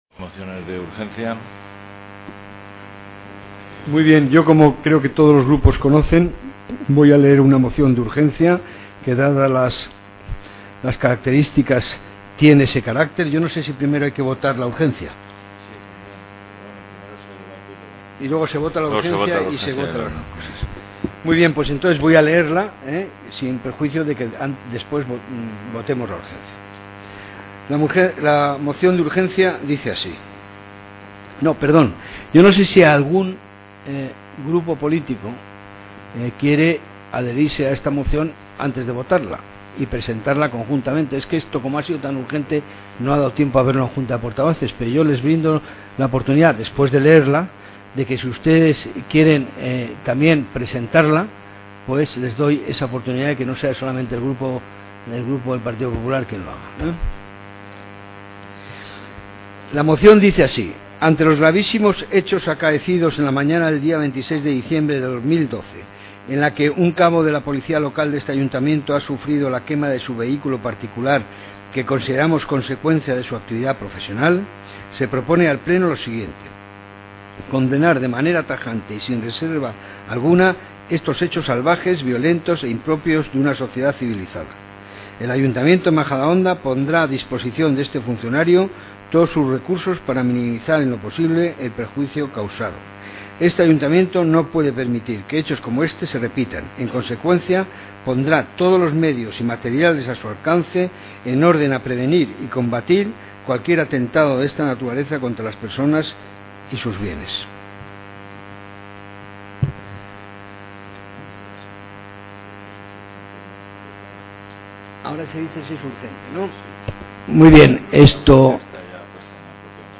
Aquí tenéis el audio del pleno (2,5 MB) y el acta donde se recogen las declaraciones del Alcalde.